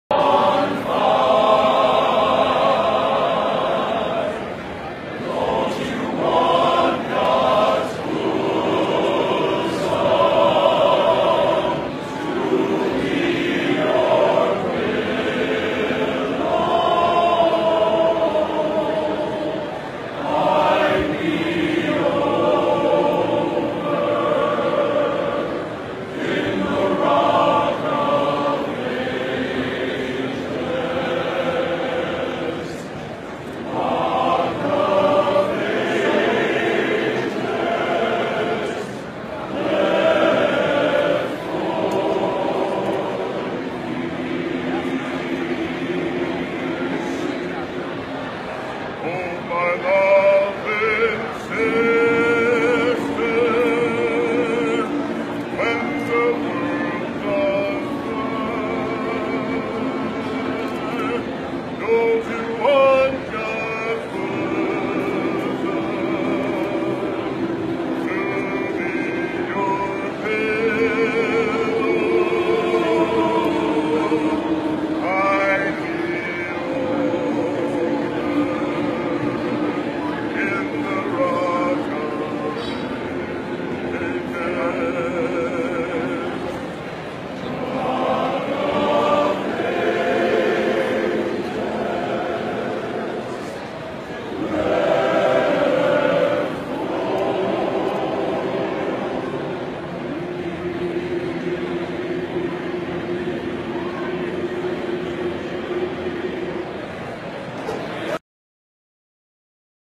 Genre: Spiritual Traditional | Type: Surround / Table
I'm sure someone can identify the soloist!